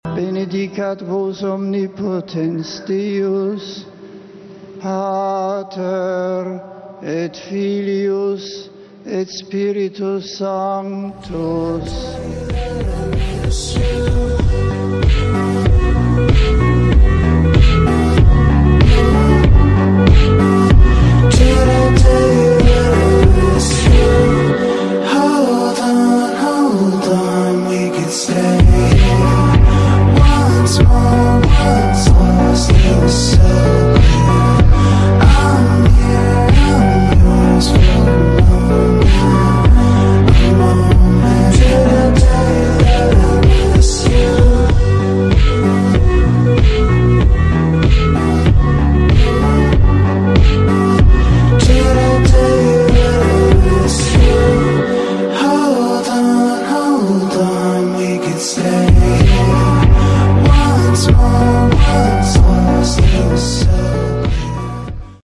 Easter Sunday mass 24 April sound effects free download